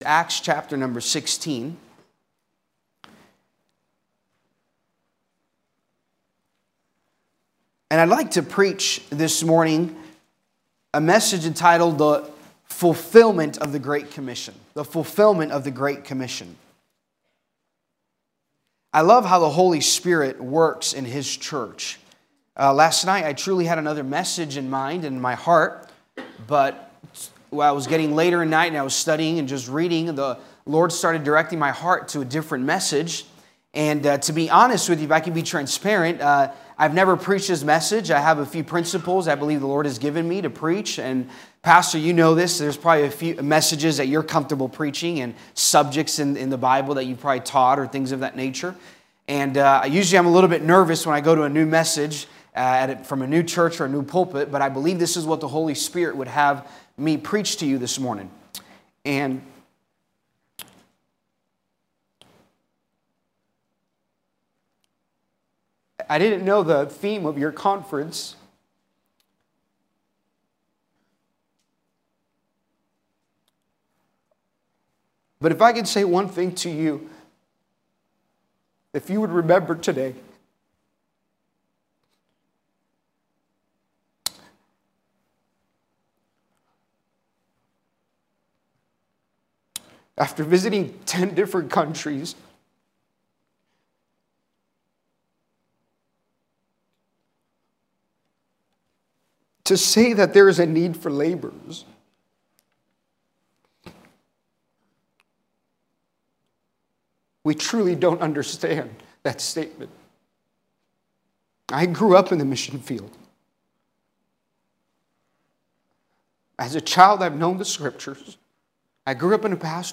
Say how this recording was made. Sunday Morning • October 20, 2024